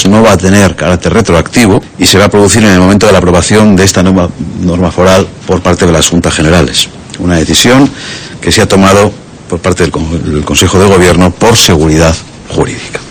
Ramiro González, diputado general de Álava, sobre el cheque-bebé